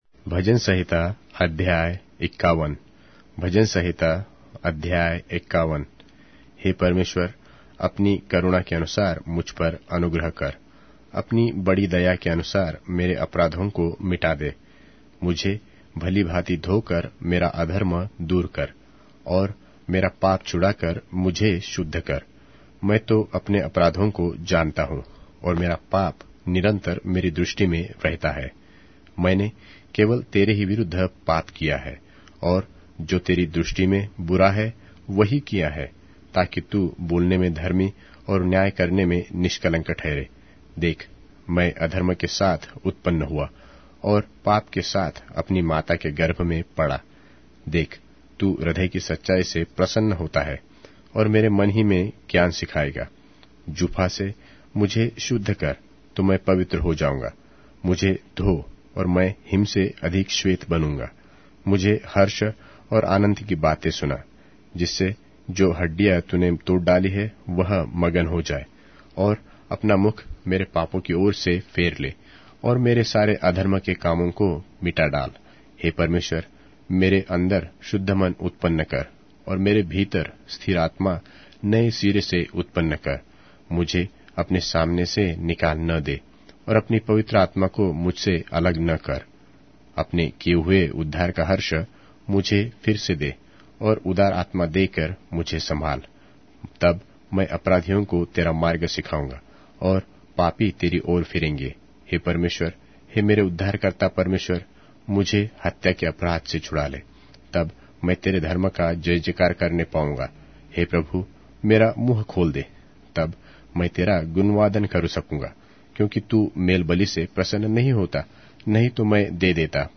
Hindi Audio Bible - Psalms 47 in Gntbrp bible version